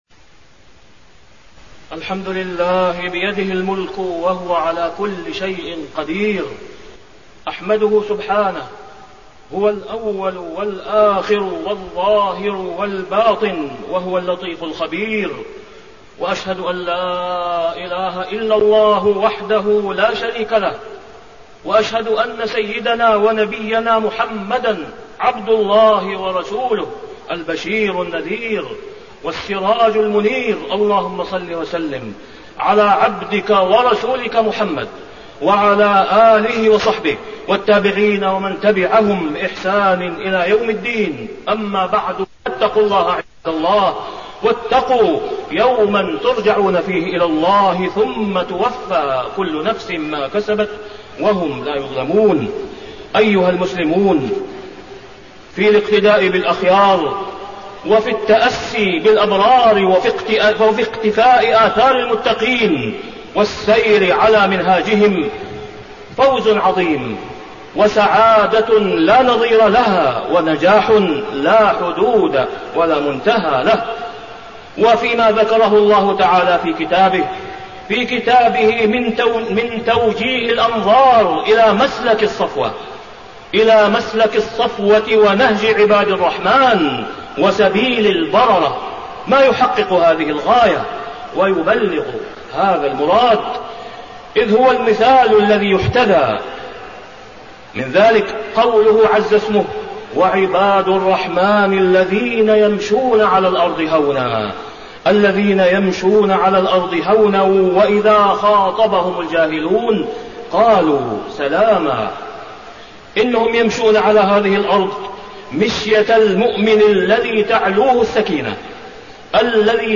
تاريخ النشر ٢٤ ربيع الثاني ١٤٣١ هـ المكان: المسجد الحرام الشيخ: فضيلة الشيخ د. أسامة بن عبدالله خياط فضيلة الشيخ د. أسامة بن عبدالله خياط صفات المتقين